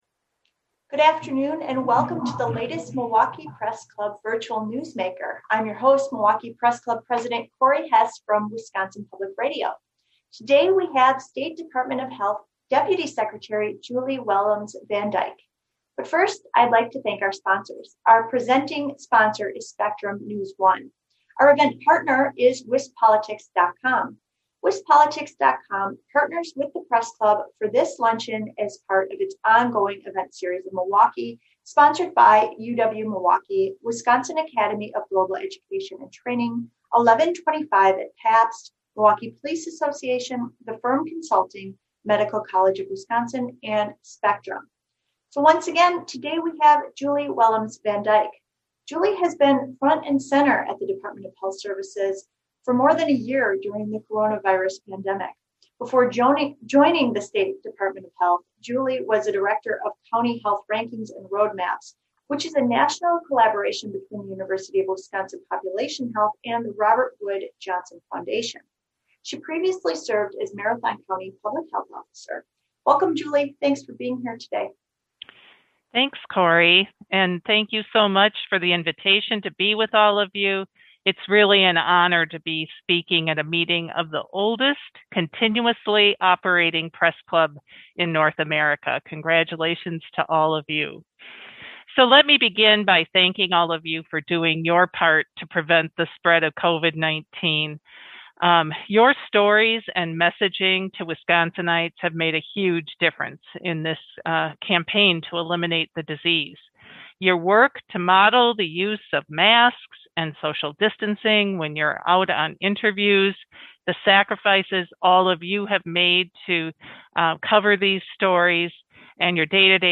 » An Interview with Julie Willems Van Dijk, deputy secretary of the Wisconsin Department of Health Services